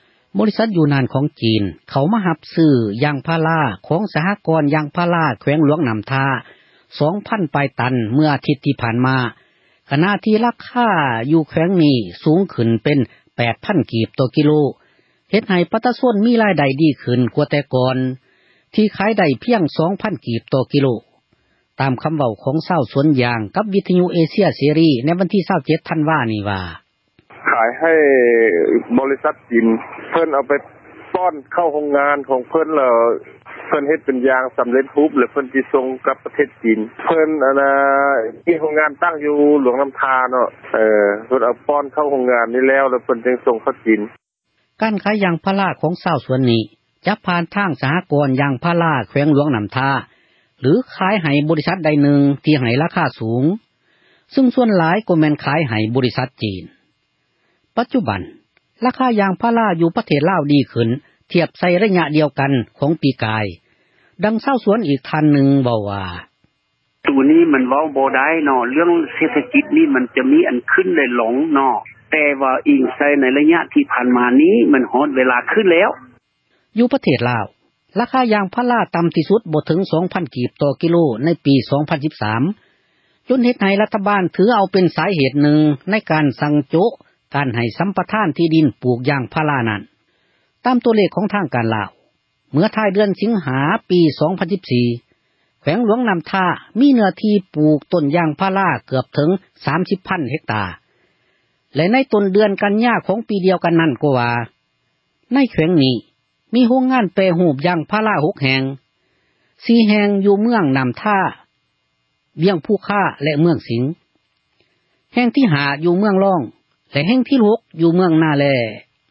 ຕາມຄຳເວົ້າ ຂອງຊາວສວນຢາງ ຕໍ່ວິທຍຸ ເອເຊັຽເສຣີ ໃນວັນທີ 27 ທັນວາ ນີ້ວ່າ:
ດັ່ງຊາວສວນຢາງ ອີກຜູ້ນຶ່ງ ເວົ້າວ່າ: